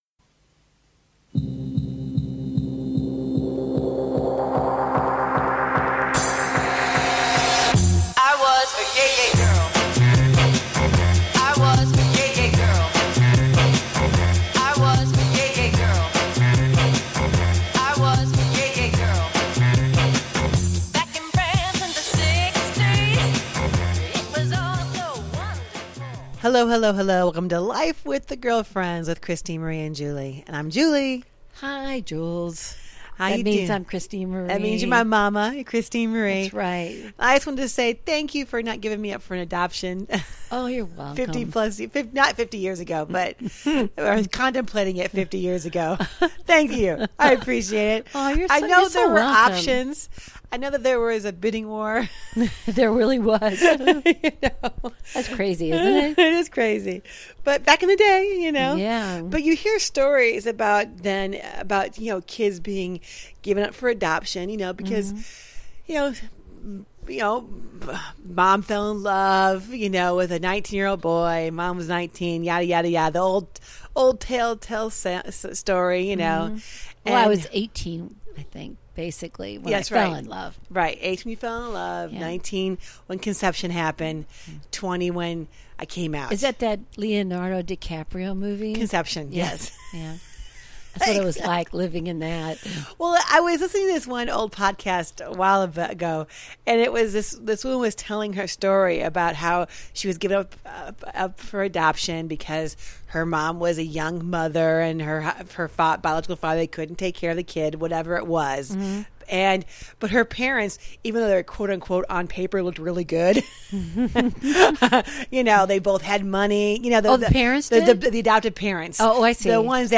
Talk Show Episode
This mother/daughter coaching duo shares their everyday thoughts on relationships, family, hot topics and current events, and anything that tickles their fancy with warmth, wit, and wisdom.
And join the girlfriends up close and personal for some daily chat that’s humorous, wholesome, and heartfelt.